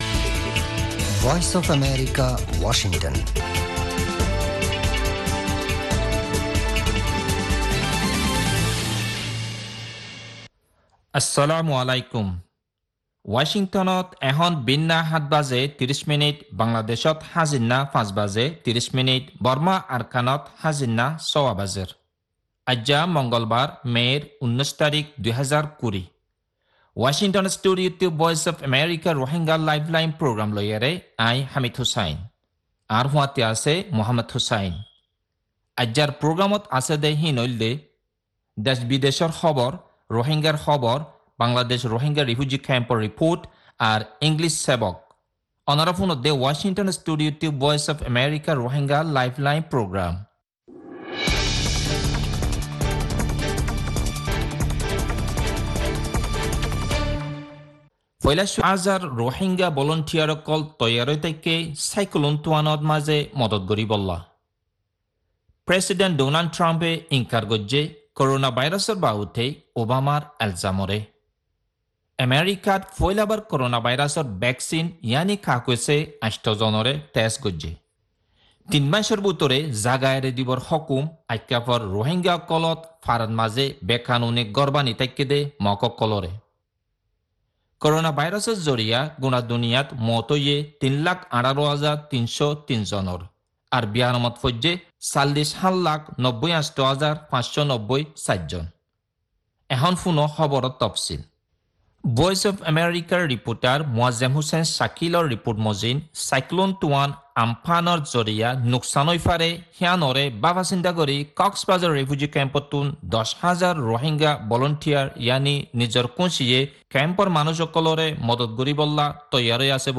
Rohingya Broadcast 05.19.2020
News Headlines: